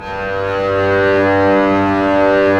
Index of /90_sSampleCDs/Roland - String Master Series/STR_Cbs FX/STR_Cbs Sul Pont